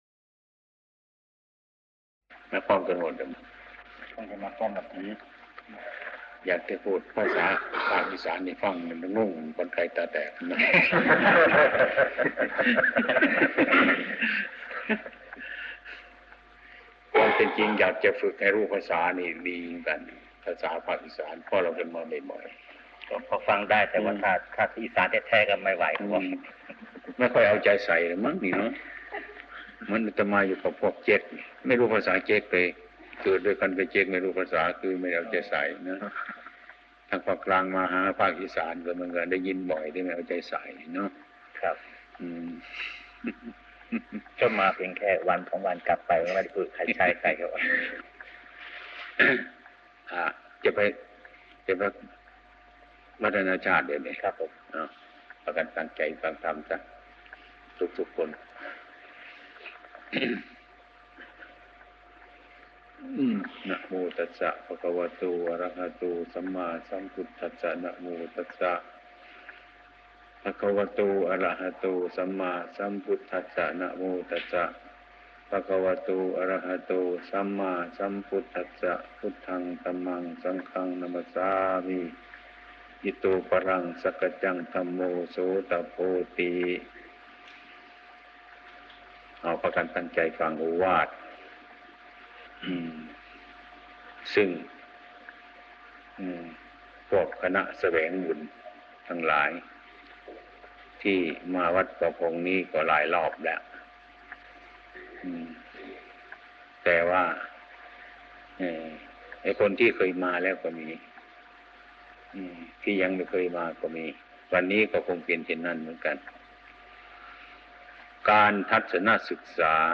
เทศน์รับเทียนพรรษา, ตอบปัญหาธรรมะ วัดหนองป่าพง 26 ก.ค. 2523
| in พุทธศาสนา ตอบปัญหาธรรมะ วัดหนองป่าพง 26 ก.ค. 2523 วัดหนองป่าพง สูตรแก้ทุกข์ เทศน์รับเทียนพรรษา เอกสาร หลวงปู่ชา สุภทฺโท ธรรมเทศนา 59:08 minutes (54.14 MB) » Download audio file 175 downloads 16 plays